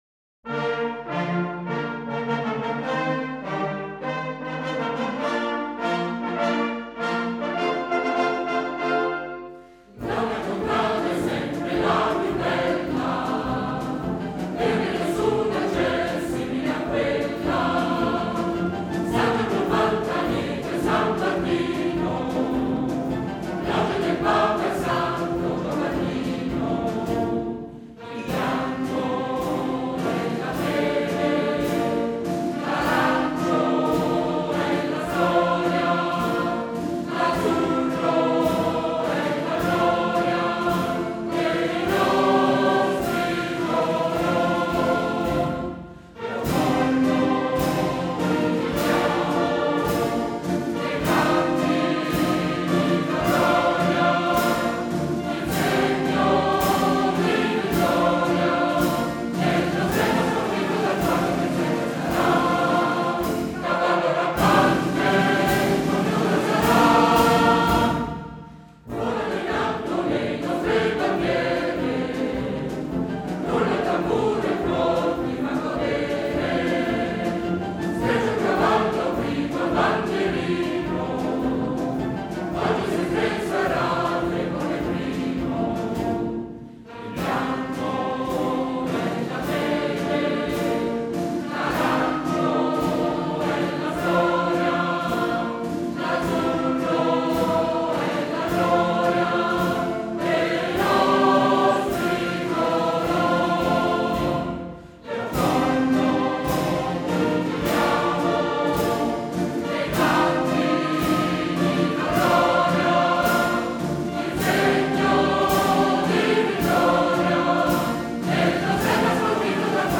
Versi e musica dei Maestri Adriano Braconi e Salvatore Cintorino – L’inno è un 2/4 recentemente mutato l’attacco in due tempi ed i contradaioli hanno aggiunto recentemente una nuova strofa cantata attualmente.